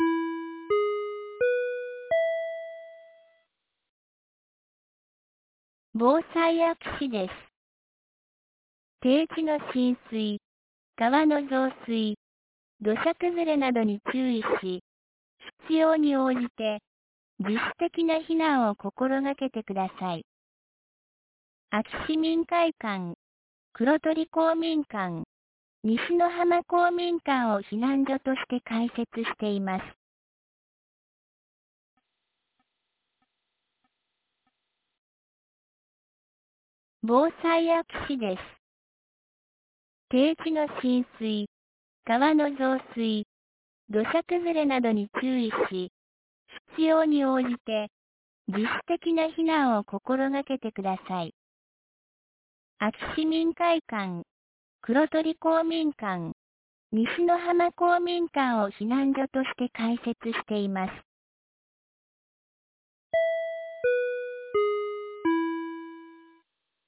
2023年06月02日 10時56分に、安芸市より安芸へ放送がありました。
放送音声